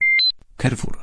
Dźwięki ostrzegawcze Carrefour
Dźwięki ostrzegawcze Carrefour Pobierz gotowe komunikaty głosowe / alarmy ostrzegawcze w formacie MP3.